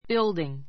bíldiŋ